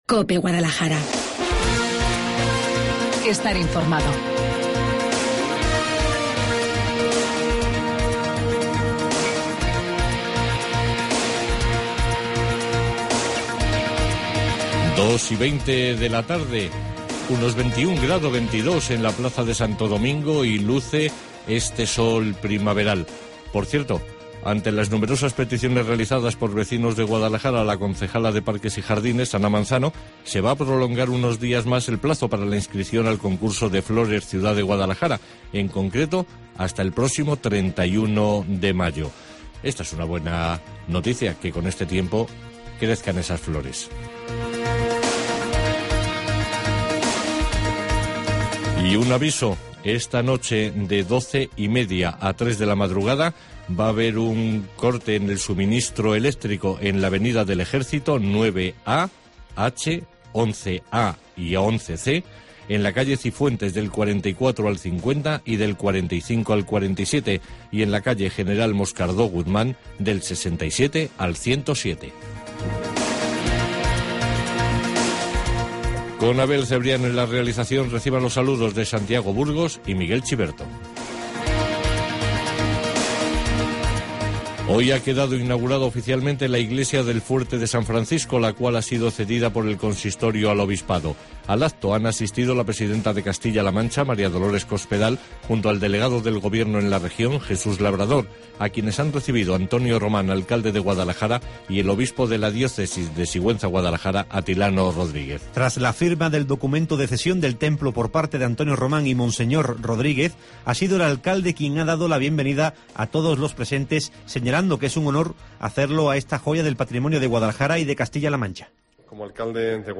Informativo Guadalajara 22 DE MAYO